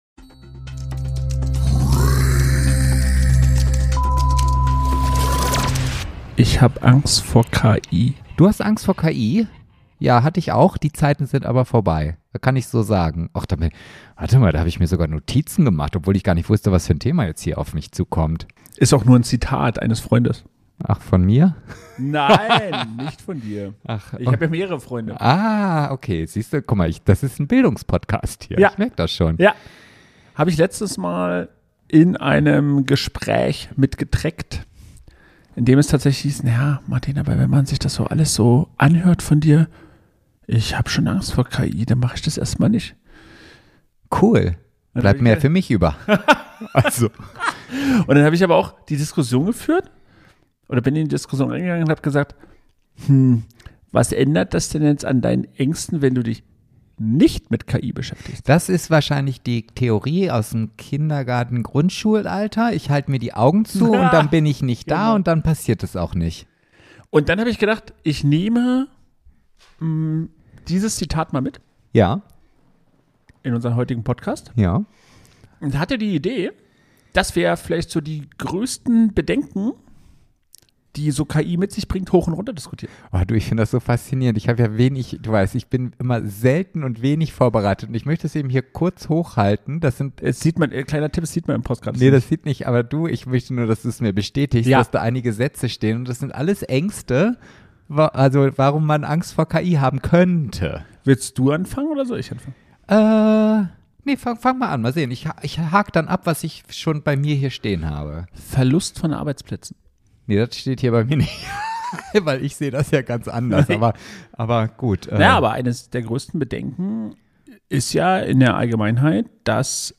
Wir sprechen über Jobverlust-Mythen, Datenschutz-Paranoia, Fake-News-Wahnsinn, KI-Model-Schönheitswettbewerbe und darüber, warum bald KI mit KI telefoniert, während wir am Pool liegen. Harte Fakten treffen auf noch härtere Sprüche. Humor inklusive.